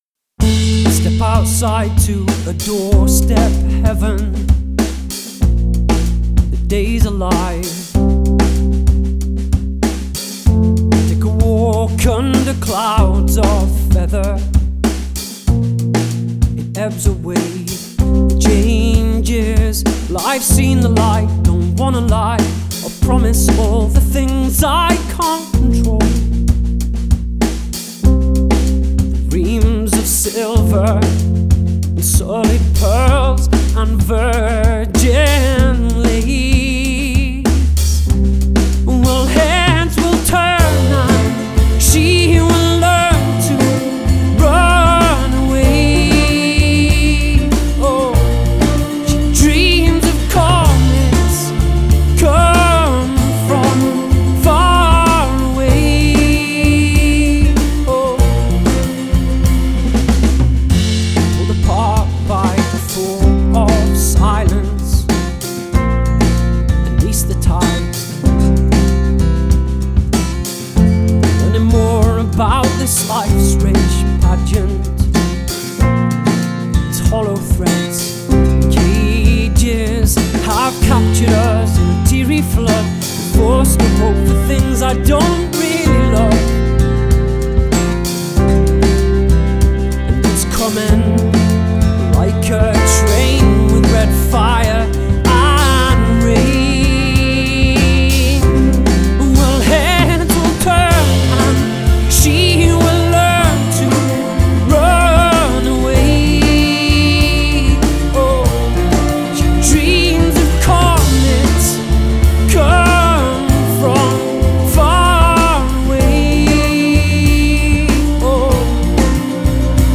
Genre: Indie Rock/ Indie Folk